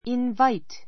inváit イン ヴァ イ ト